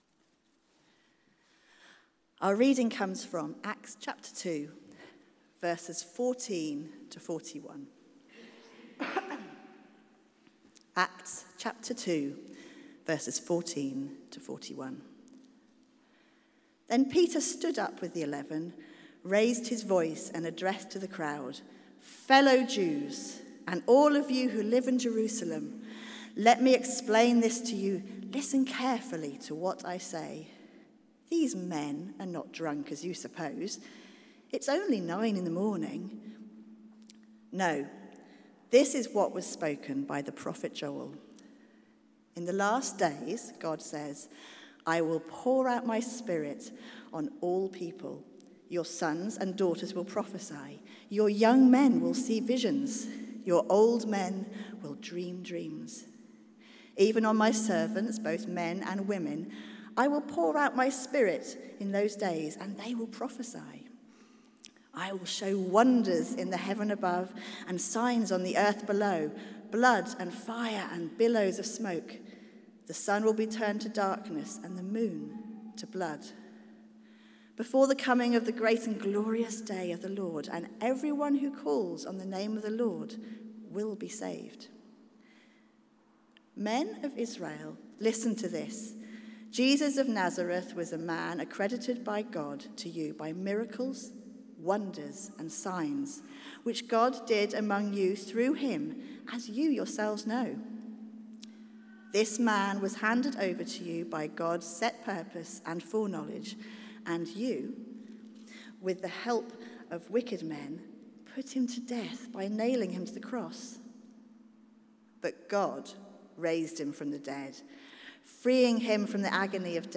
Theme: The Holy Spirit Points us to Jesus Sermon